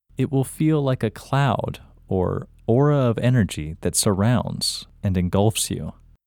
LOCATE OUT English Male 28